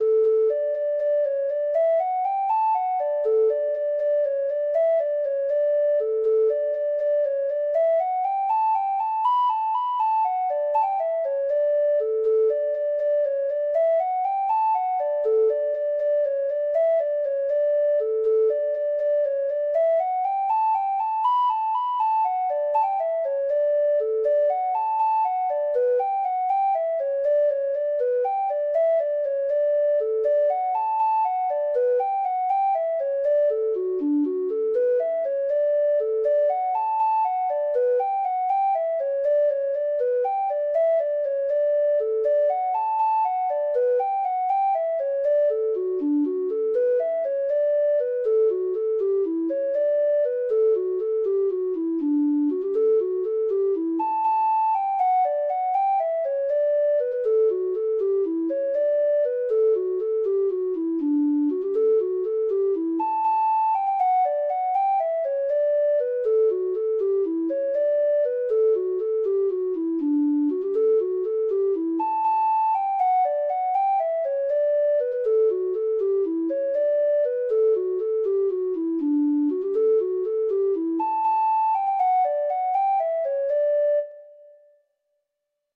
Treble Clef Instrument version
Traditional Music of unknown author.